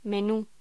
Locución: Menú
voz